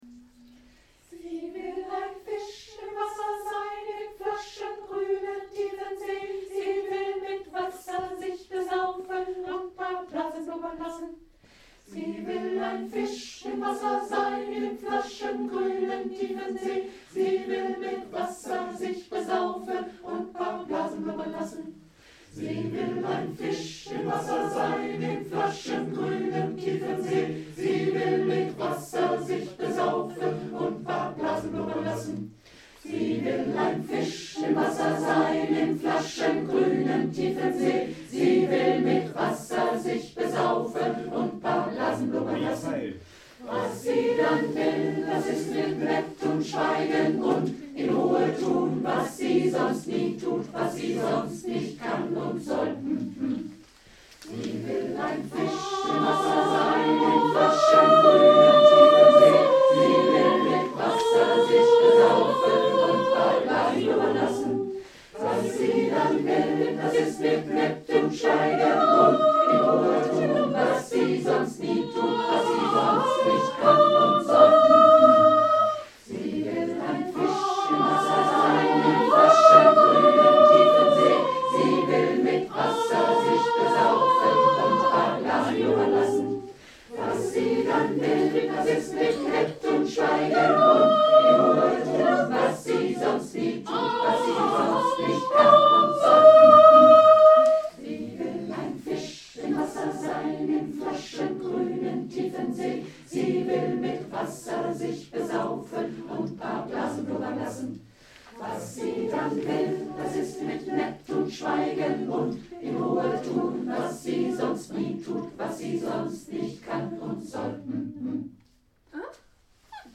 Die folgenden Stücke wurden in einem 3 1/2-stündigem Chorvergnügen erarbeitet: